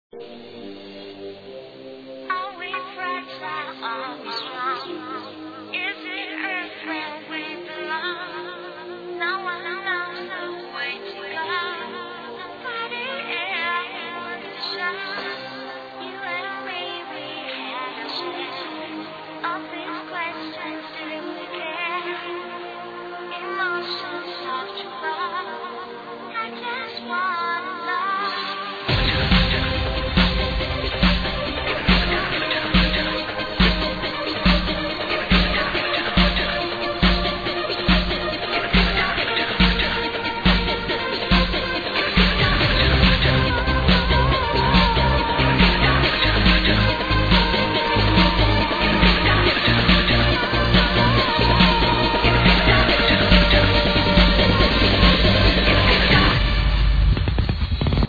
Arrow Help Me ID this other tite vocal track